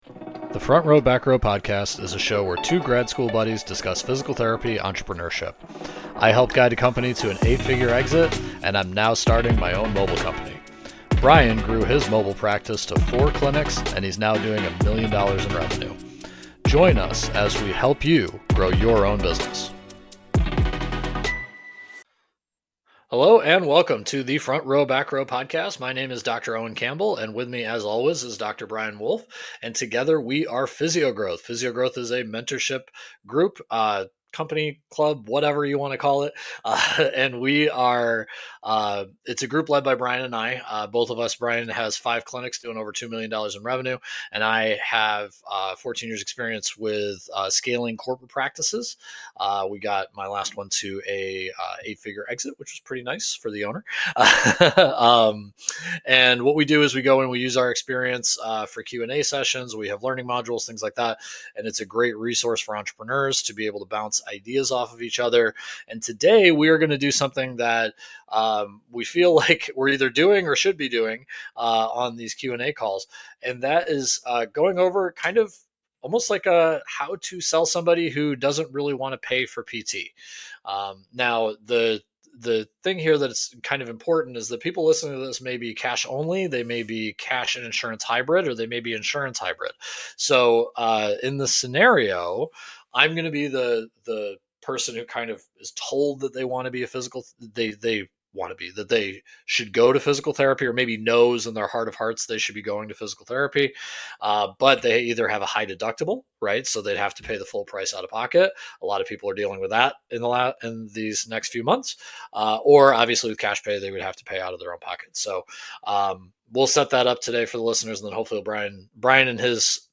podcast episode where we go over a sales call and role play between a time and cost sensitive physical therapy client
On today’s episode we pull an exclusive example from our mastermind group with a sales role play.